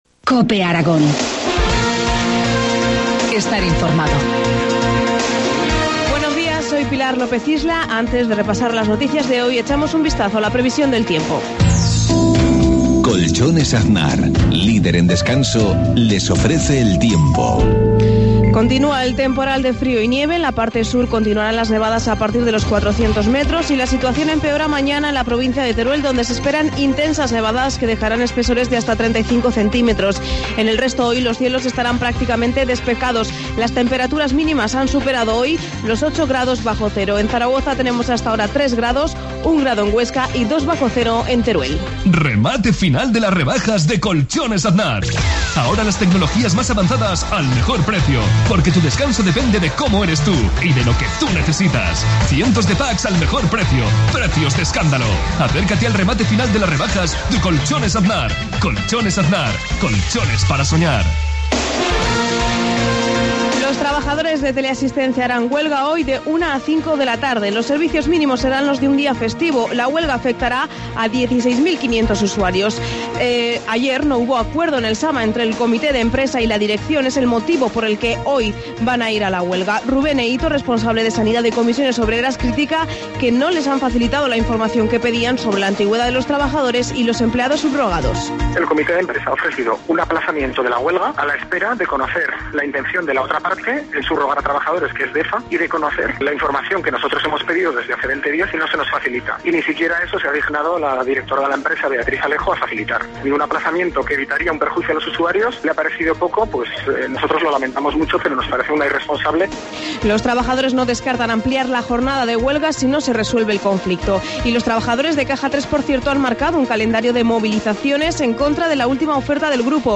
Informativo matinal, 27 de febrero, 7.53 horas